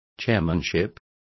Complete with pronunciation of the translation of chairmanships.